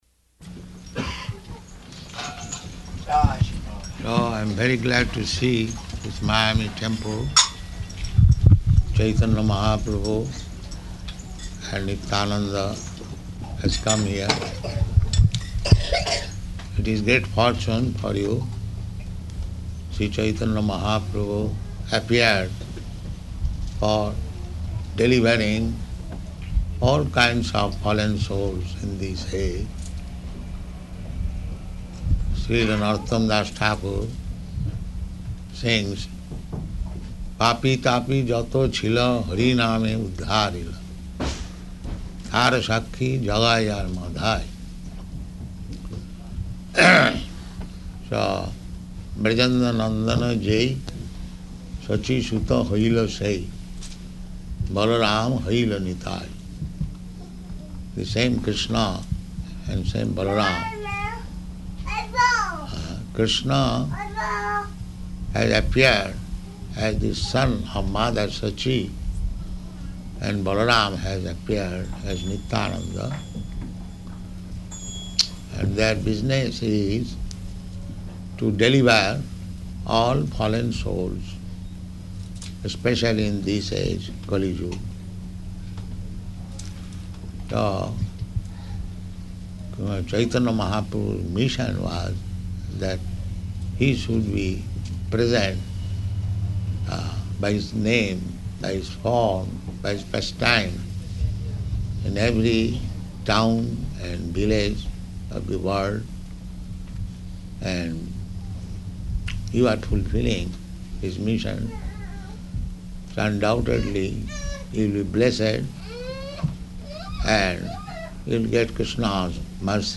Arrival Lecture
Location: Miami